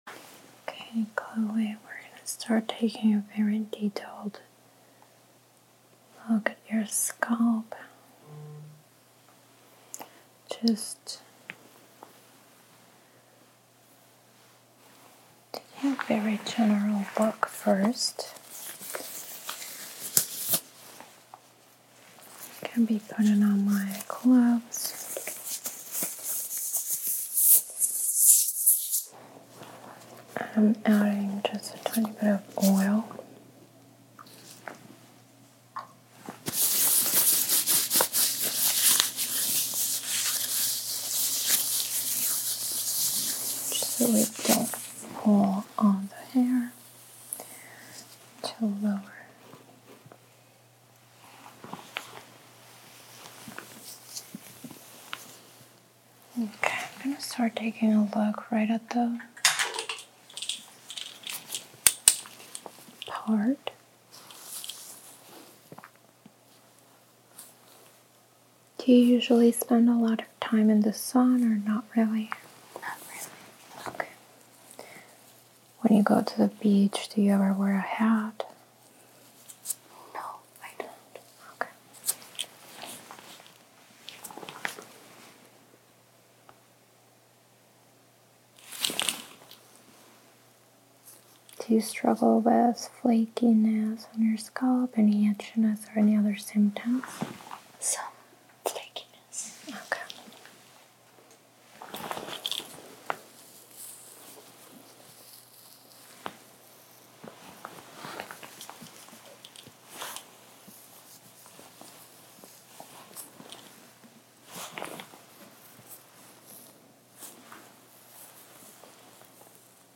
ASMR Scalp Spa Experience | sound effects free download
Water Sounds, Tingles, Hair Washing Triggers & Soft-Spoken Relaxation